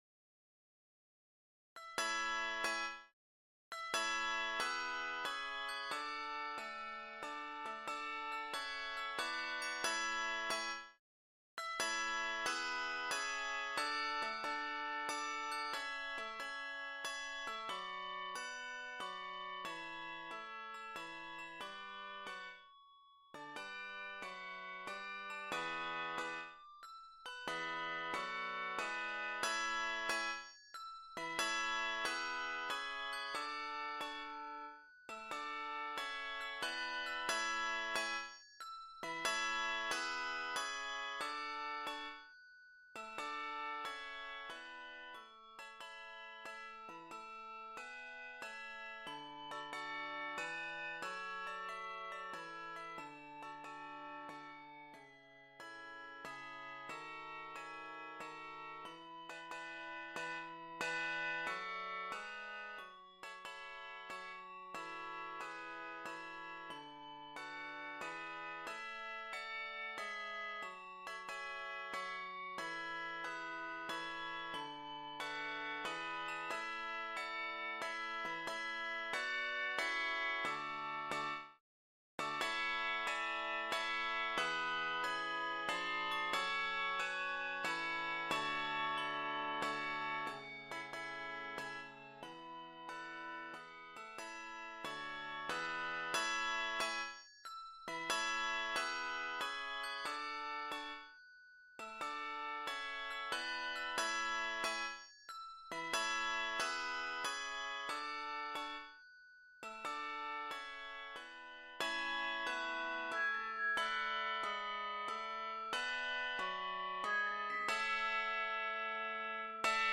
Keys of A Major, Bb Major, and D Major.